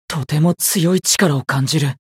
觉醒语音 能感到十分强大的力量……！